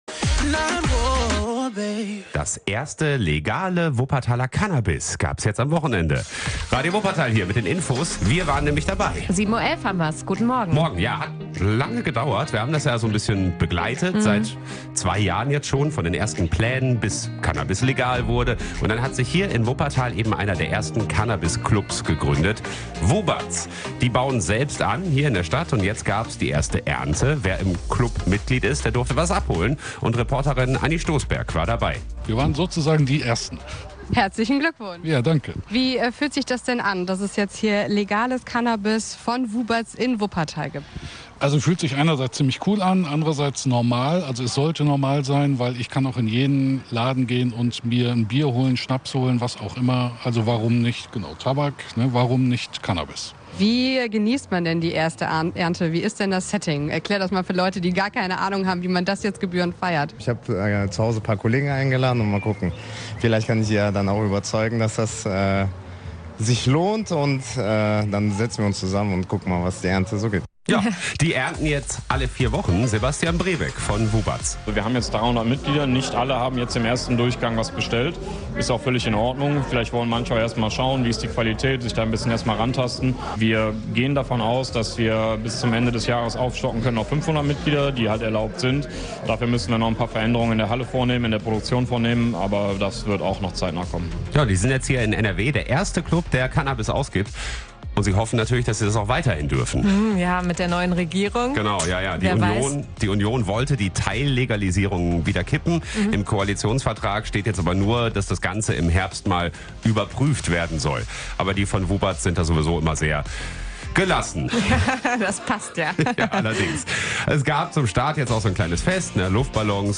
play_circle play_circle Reportage: Wie kriegt man das Cannabis? play_circle Abspielen Anzeige © Radio Wuppertal © Radio Wuppertal Anzeige play_circle play_circle Wer kriegt das erste Cannabis?